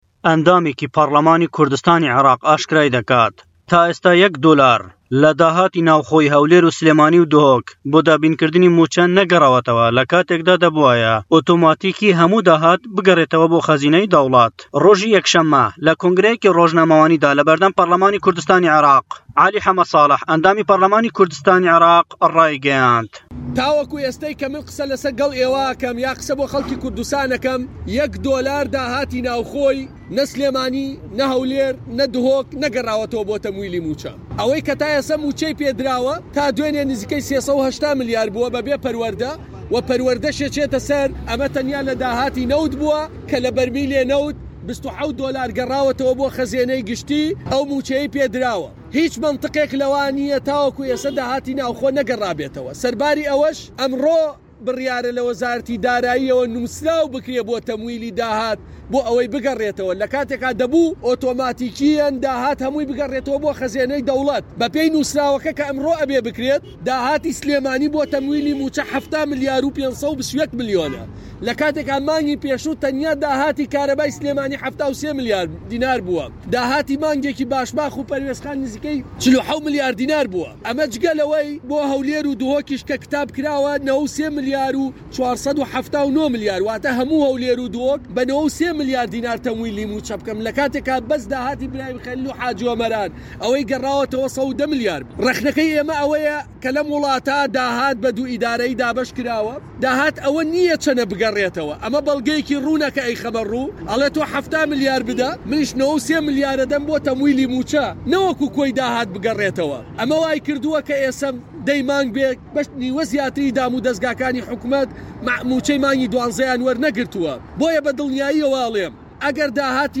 دەقی ڕاپۆڕتی پەیامنێر